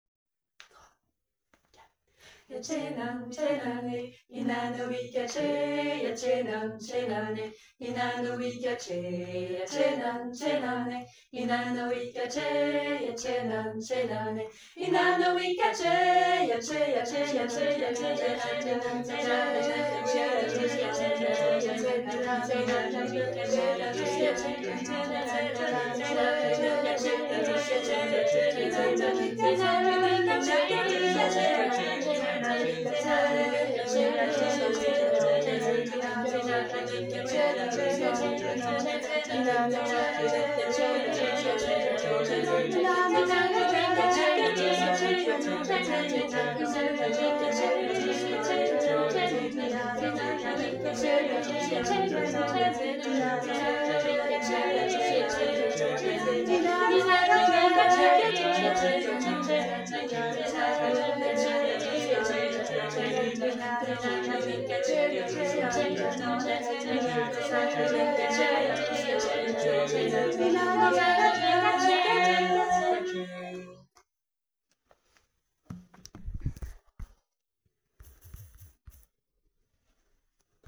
Retrouver nos chants, nos enregistrements, pour chanter entre femmes à Gap